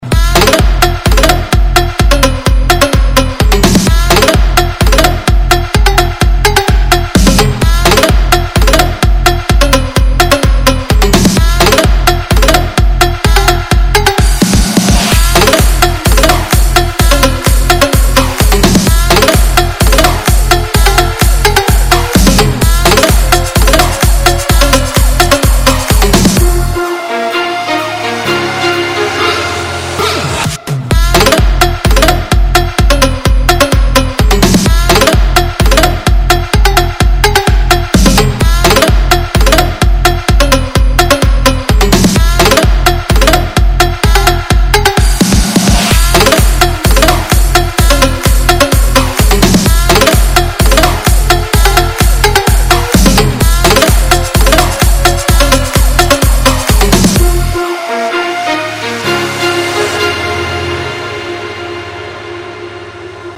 • Качество: 128, Stereo
громкие
electro house
звонкие
Интересная трещотка в качестве музыки